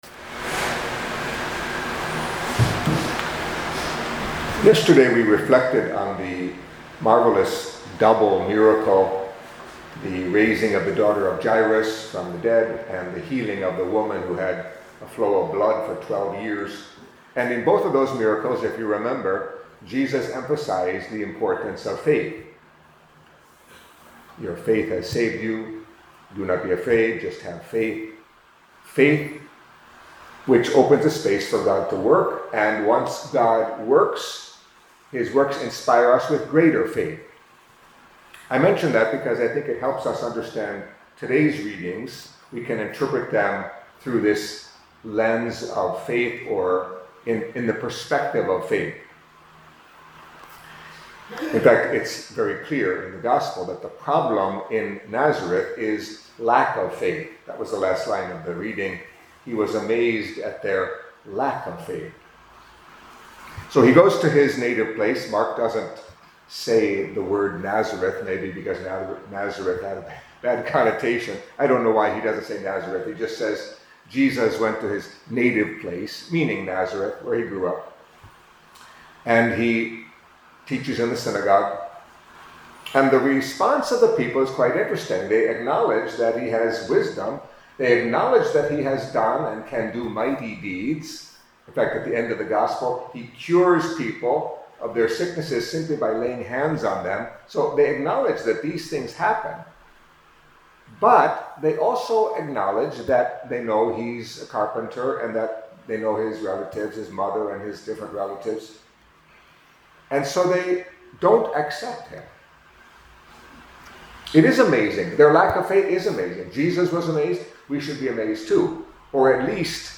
Catholic Mass homily for Wednesday of the Fourth Week in Ordinary Time